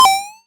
monster_spawn.mp3